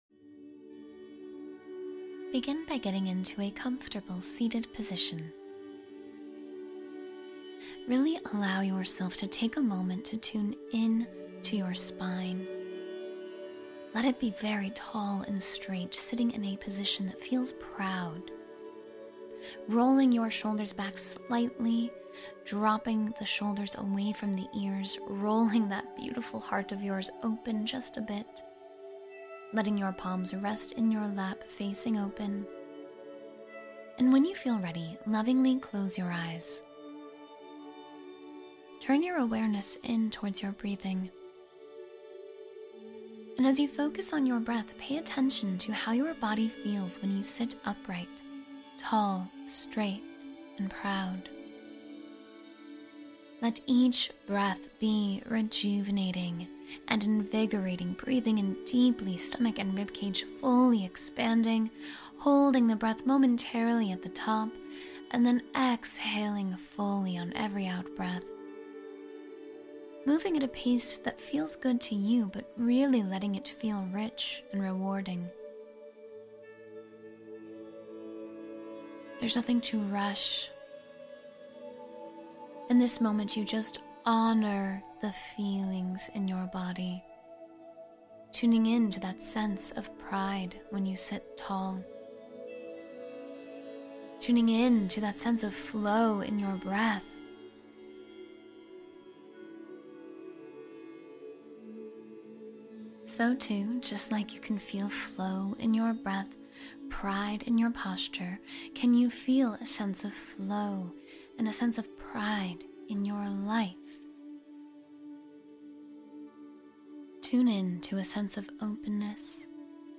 Guided mindfulness exercises